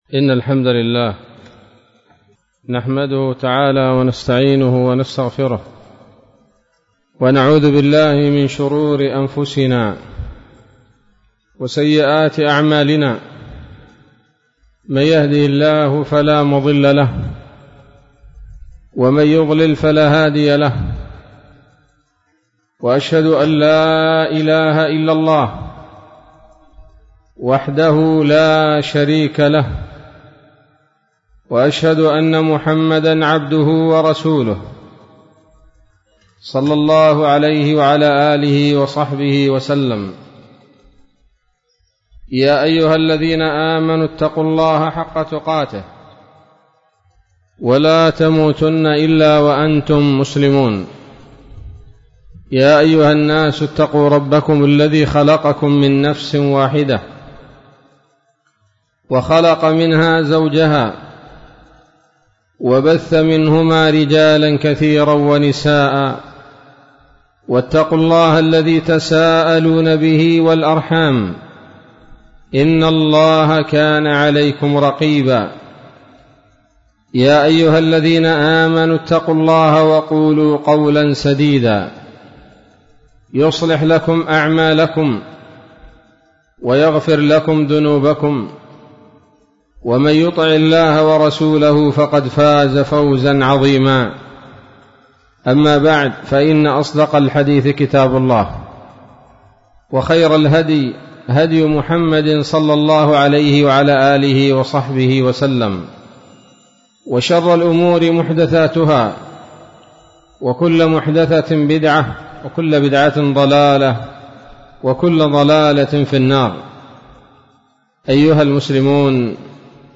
محاضرة قيمة